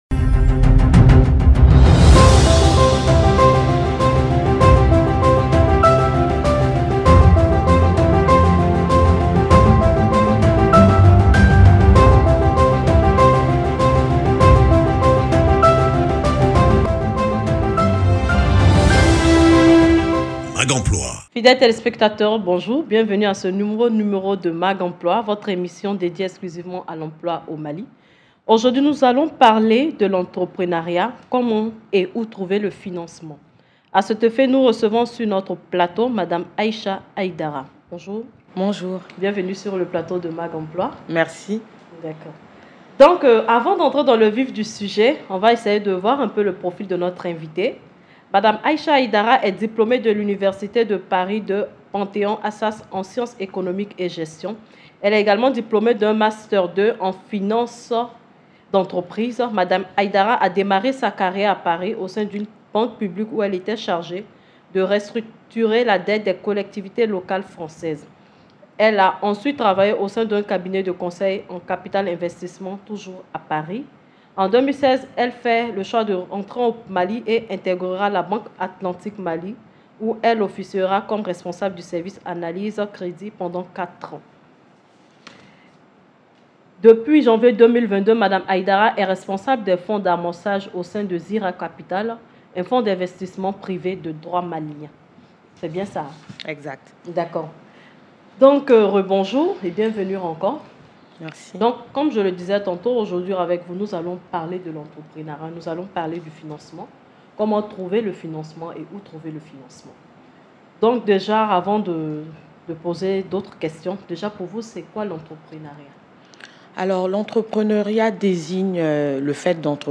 Vous Suivez Mag emploi, votre émission dédiée à l’emploi.